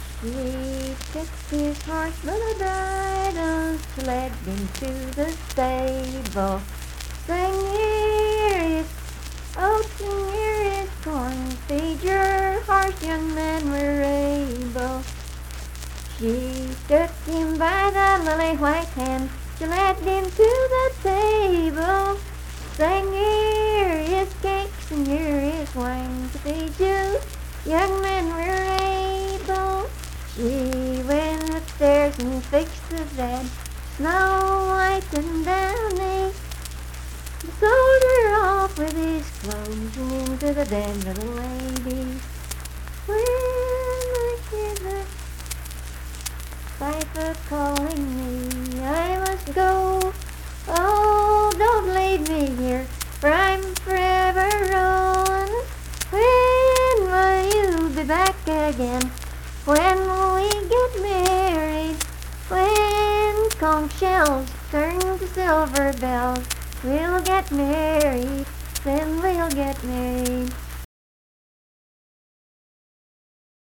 Unaccompanied vocal music
Performed in Strange Creek, Braxton, WV.
Voice (sung)